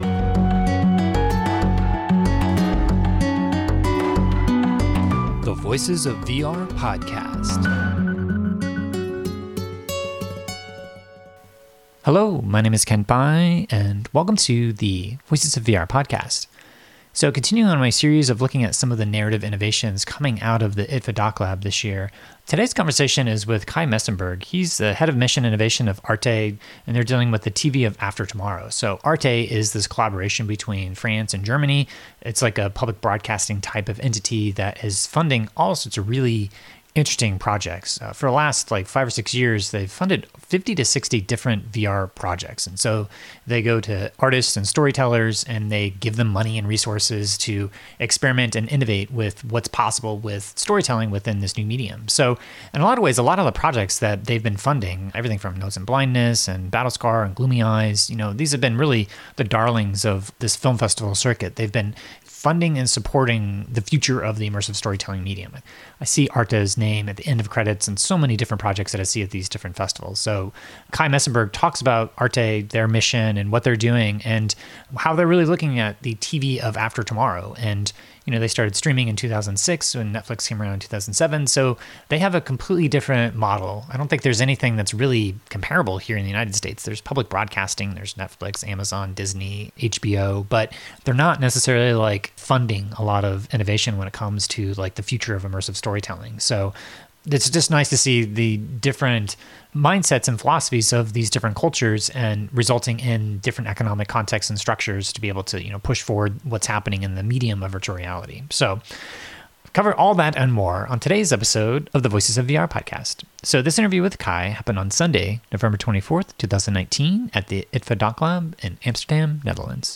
at the IDFA DocLab